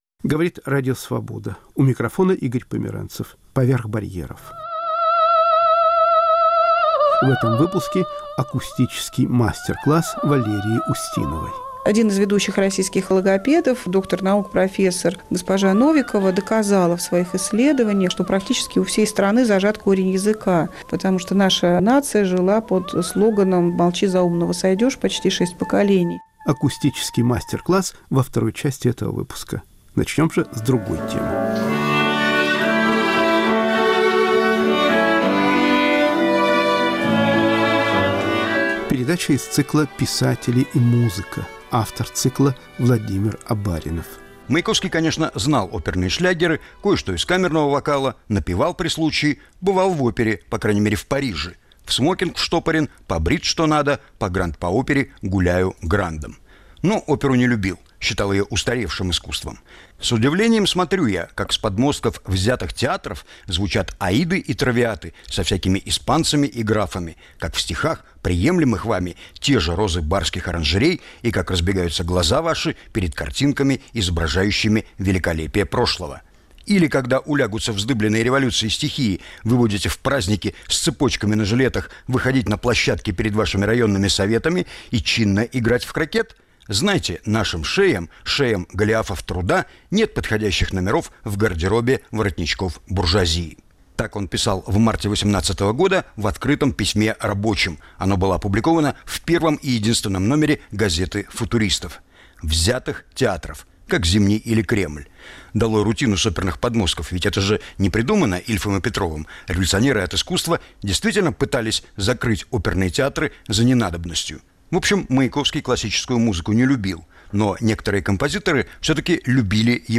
В. Маяковский и музыка. *** Акустический мастер-класс в театре на Левом берегу Днепра.*** «Родной язык» с писателем Эдуардом Тополем.*** «Мои любимые пластинки» Говорят и поют вятские бомжи.